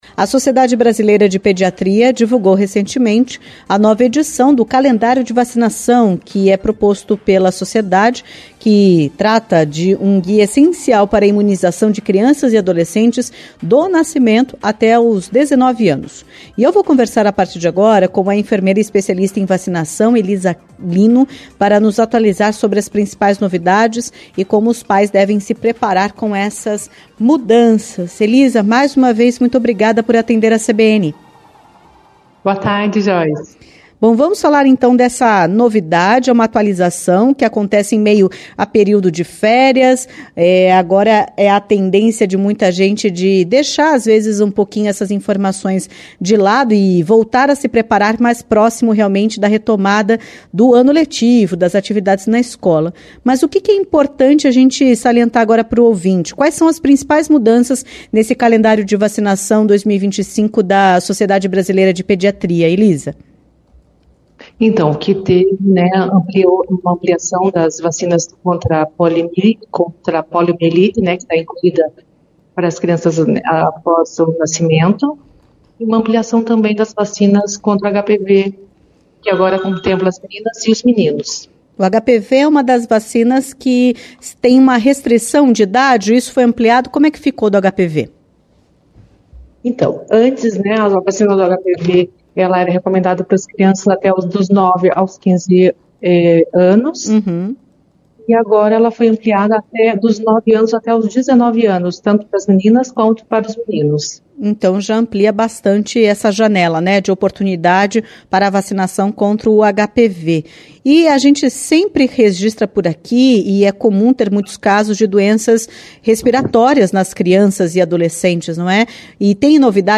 entrevista-1701.mp3